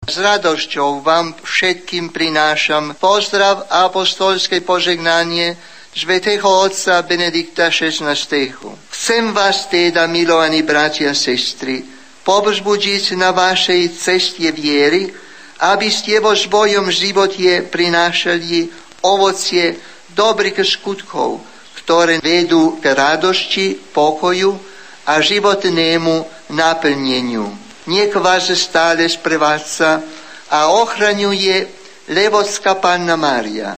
Levočská pouť vyvrcholila slavnostní mší svatou
V závěru bohoslužby apoštolský nuncius